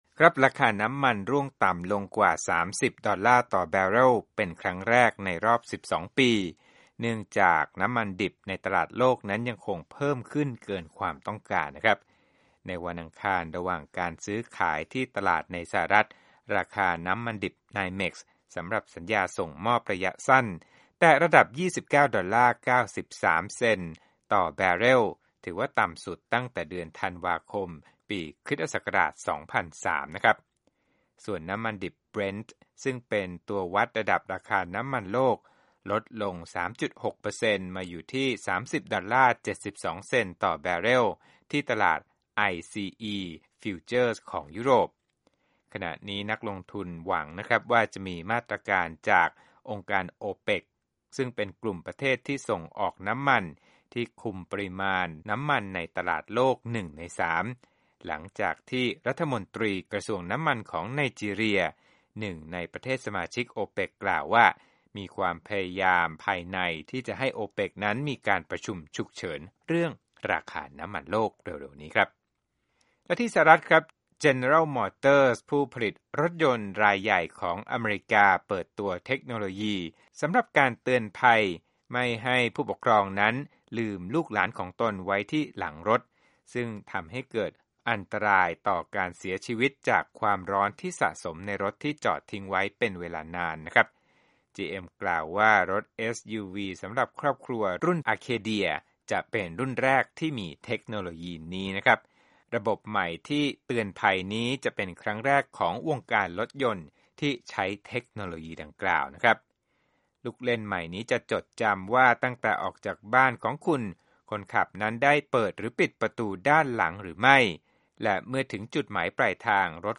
Business News